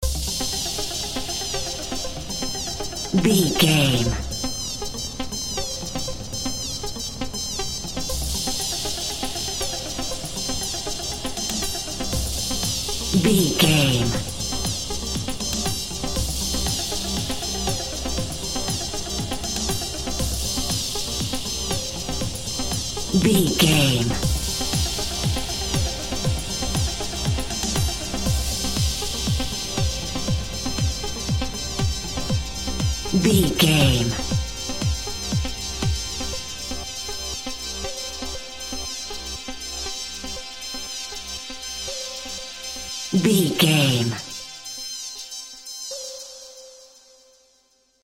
Epic / Action
Fast paced
Aeolian/Minor
dark
futuristic
groovy
aggressive
repetitive
synthesiser
drum machine
house
techno
trance
synth leads
synth bass
upbeat